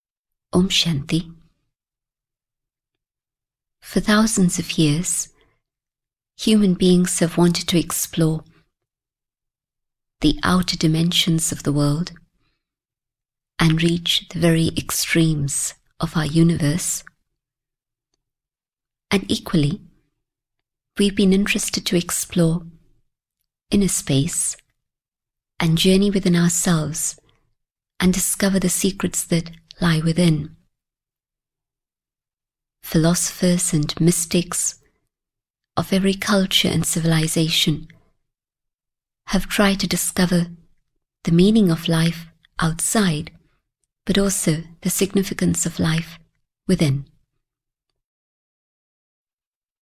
Understanding Myself (EN) audiokniha
Ukázka z knihy
Grow strong through a deeper understanding of the Self. This informative talk shows how you can develop positive attributes and strengths that will be your personal tools for life. Understand how to empower the Self by taking control of your different roles and responsibilities, both in your personal and working lives.